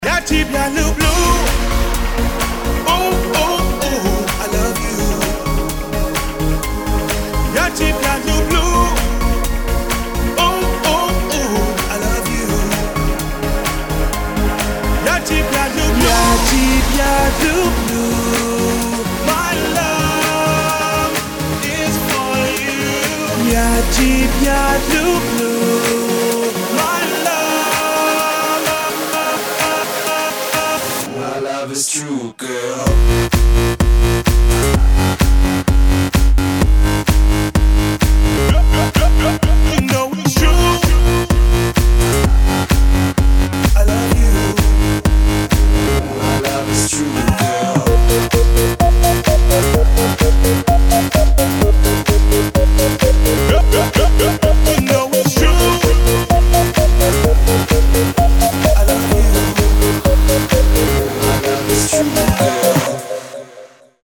• Качество: 192, Stereo
dance
club
house